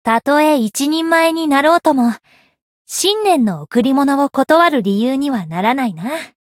灵魂潮汐-密丝特-春节（送礼语音）.ogg